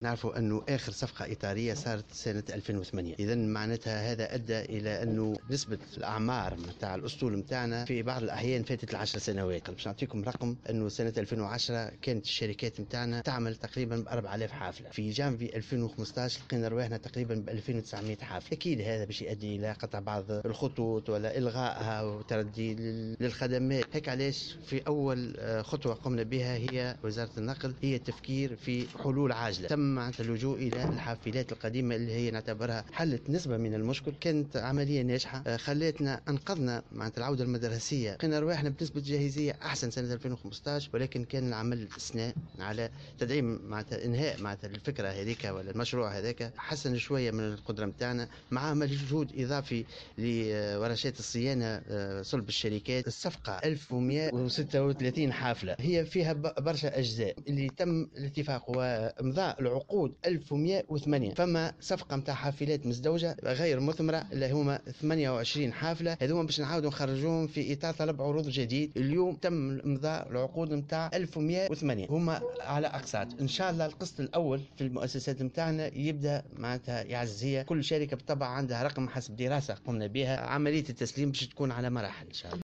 وقال الوزير في تصريح لمراسلة "الجوهرة أف أم" إن قيمة الصفقة تقدر ب 417 مليون دينار وتهدف إلى تعزيز شركات النقل الجهوية والوطنية وتحسين ظروف نقل المسافرين، خاصة وأن معدل أعمار الحافلات في تونس قد فاق أحيانا 10 سنوات، وفق تعبيره.